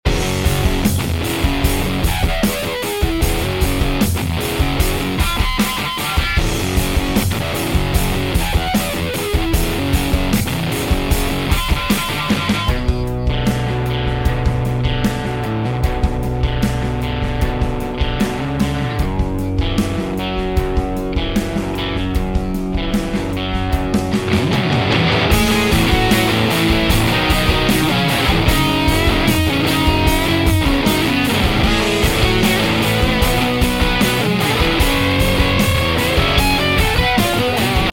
For today’s Three Sounds reel we are taking a look at the Dude Incredible from EAE. Inspired by the tones of Steve Albini, the Dude Incredible combines a stripped down version of the Intersound IVP “tube voiced” preamp and a Harmonic Percolator fuzz. These can be used independently or stacked together to create a massive range of drive and fuzz textures.
It also has a diode toggle that changes the clipping section of the circuit and introduce more compression.